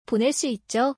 ポネ ス イッジョ？